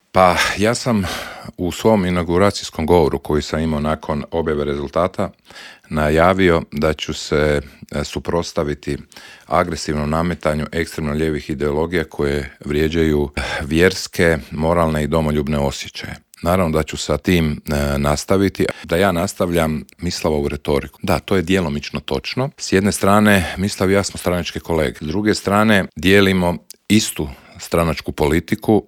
ZAGREB - U Intervjuu Media servisa gostovao je predsjednik zagrebačkog HDZ-a Ivan Matijević s kojim smo prošli aktualne teme na nacionalnoj, kao i na zagrebačkoj razini.